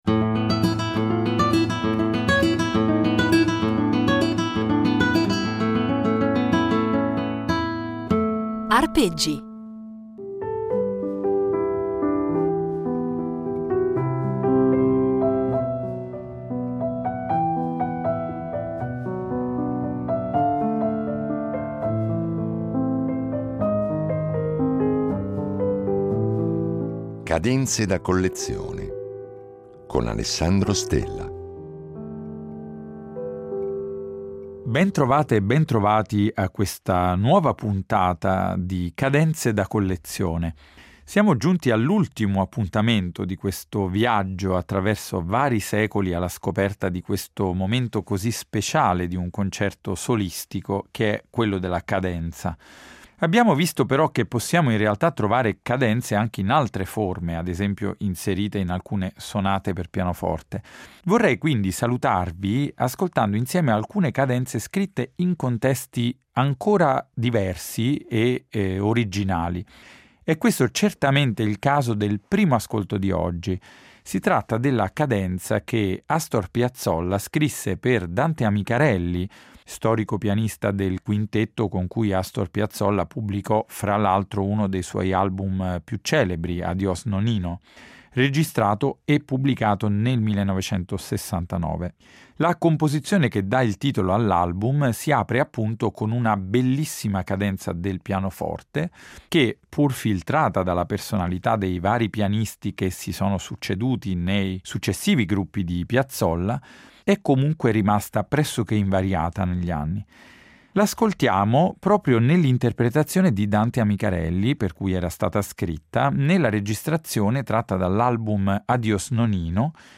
Da quelle scritte dagli autori, a volte in due distinte versioni, a quelle firmate o improvvisate dagli interpreti, dal concerto alla sonata perché come vedremo la pratica della cadenza compare anche nella musica da camera, una bella carrellata di esempi memorabili e significativi commentati per noi da un appassionato specialista della materia.